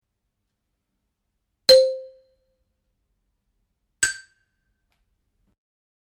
go from to high.
xilograveagudo.mp3